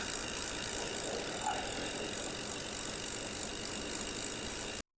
Bicycles Sound Effects - Free AI Generator & Downloads
bike-cycle-sound--aqsnjnva.wav